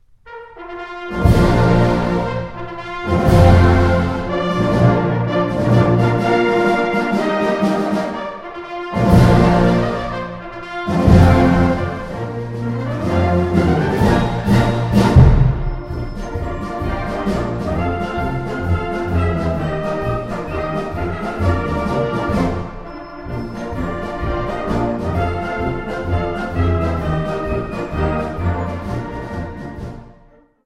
Gattung: Konzertmarsch
Besetzung: Blasorchester